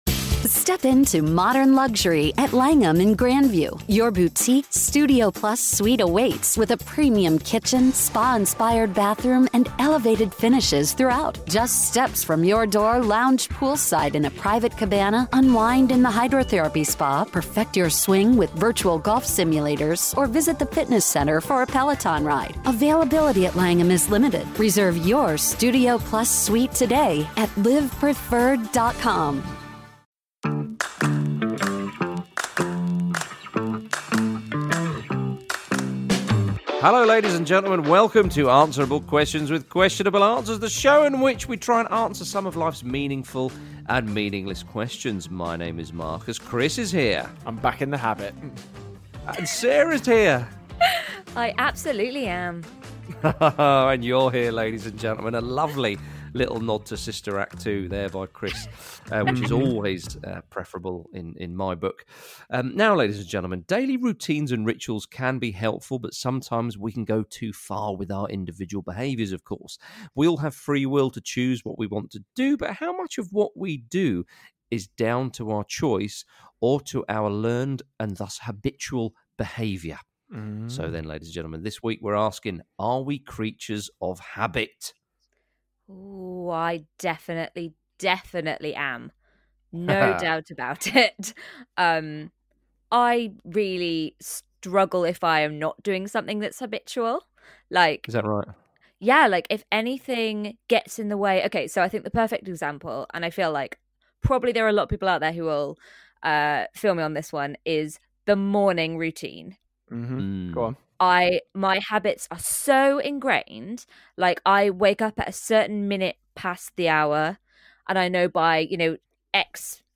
recorded this episode remotely in the safety of their own homes.